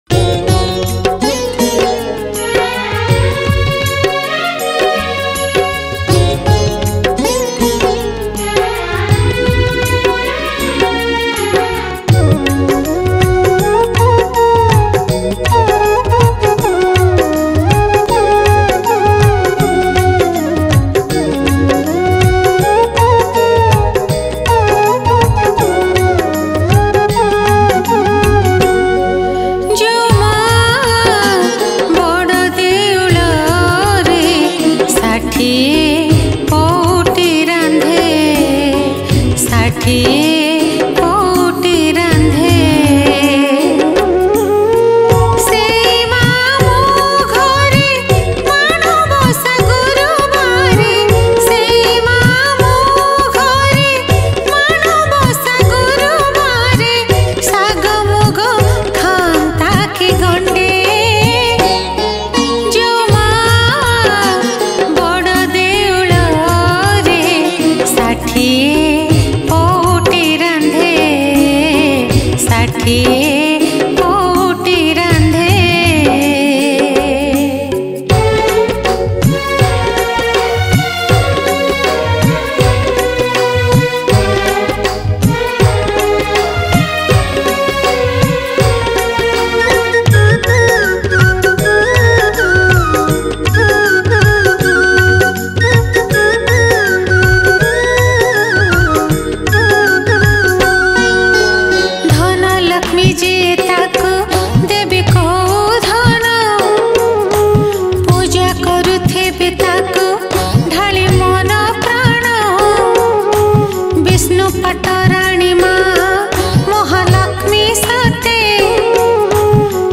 Manabasa Gurubara Bhajan Songs Download
Category : Manabasa Gurubara Bhajan